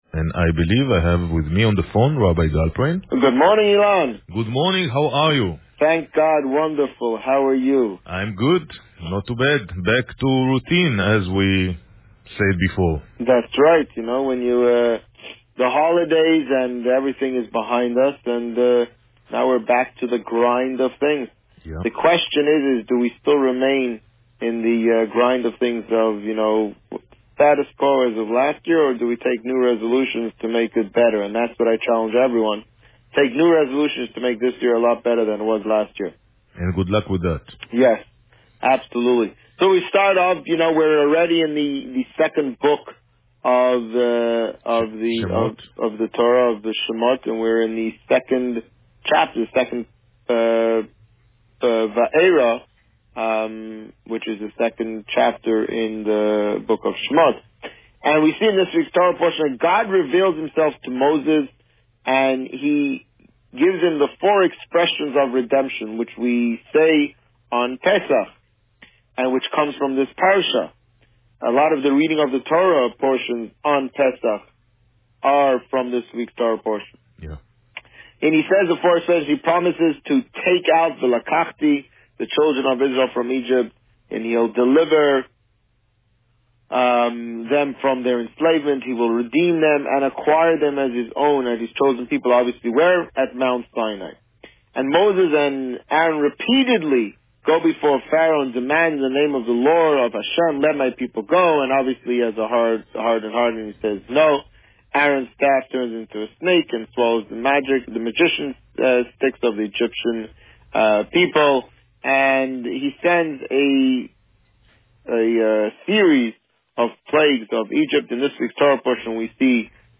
This week, the Rabbi spoke about Parsha Va'eira. Listen to the interview here.